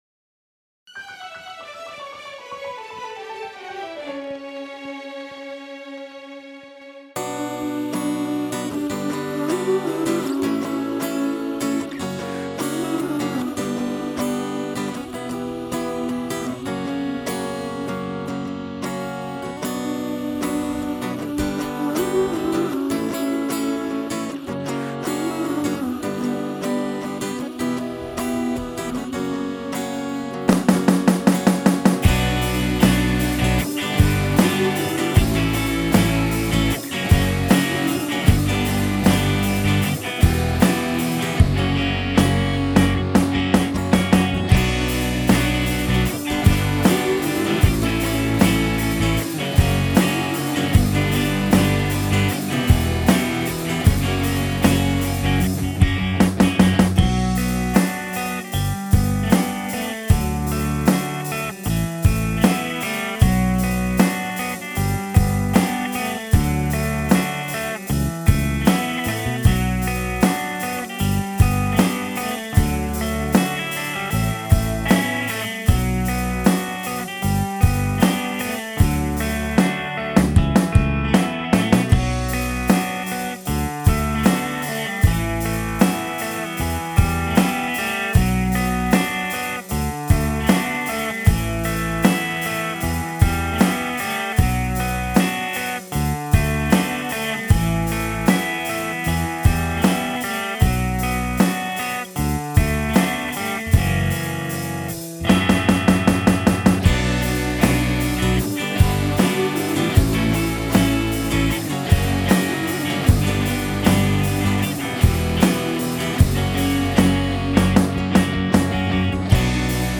track_18_vypusknoy_medlyachok.mp3